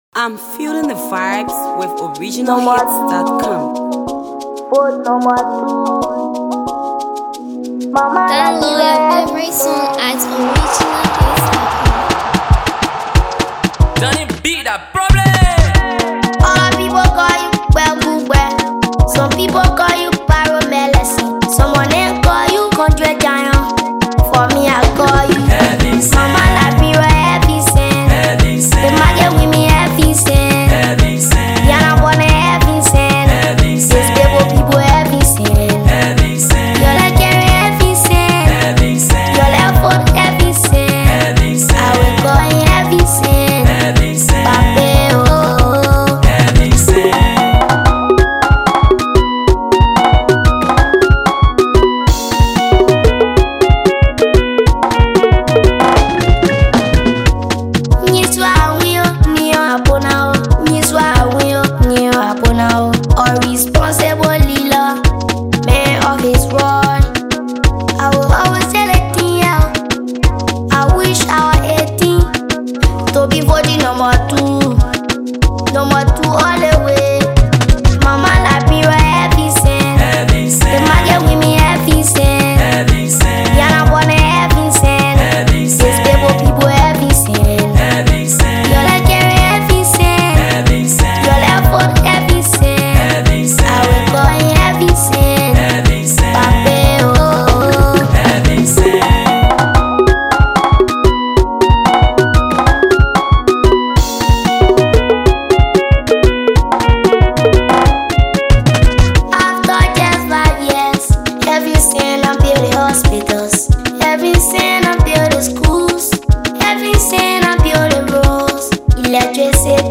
uplifting campaign song
In this catchy tune